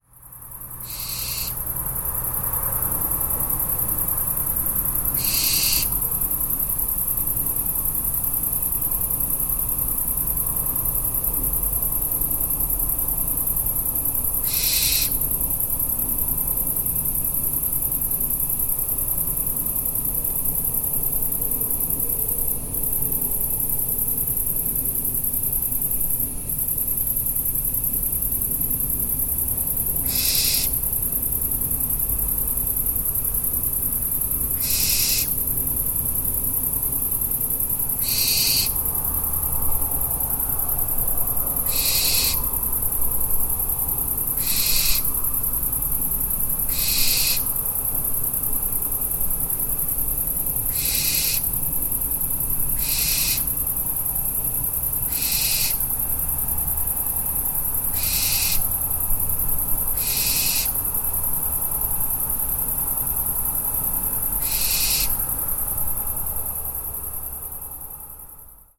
Effraie des clochers (Tyto alba)